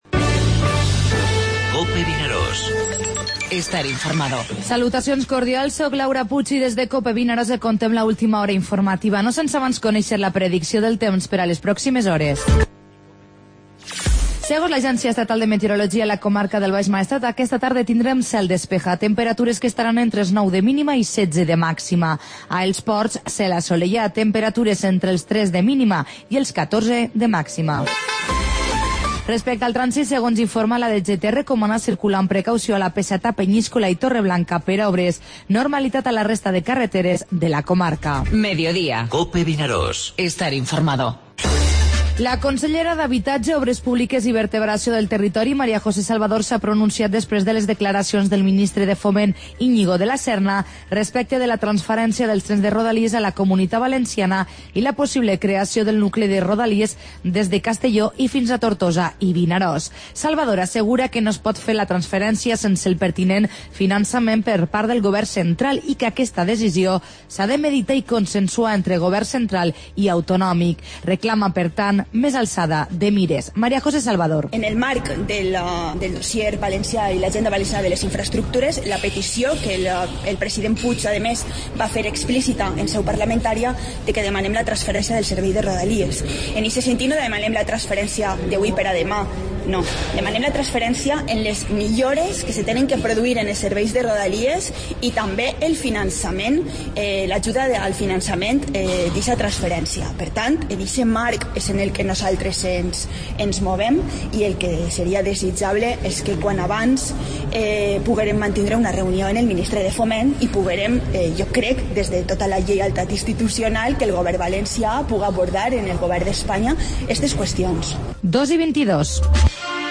Informativo Mediodía COPE al Maestrat (dimecres 1 de març)